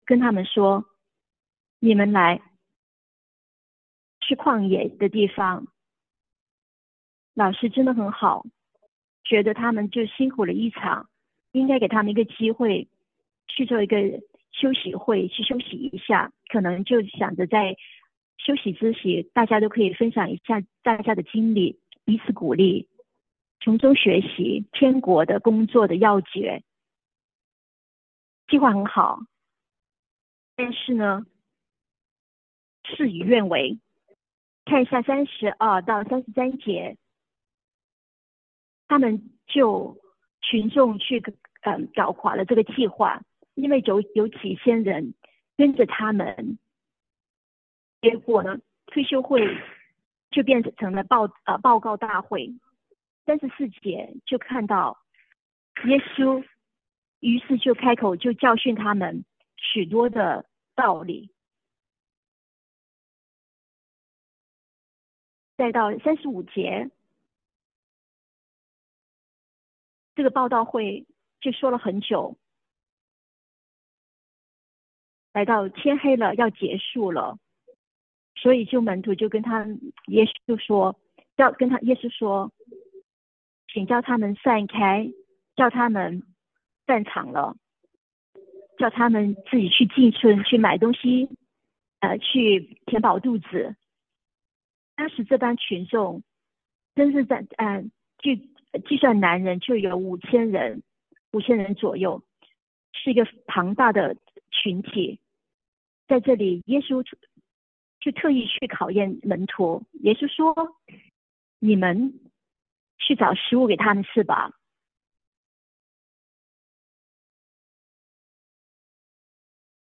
嚴厲評語 – 普通話傳譯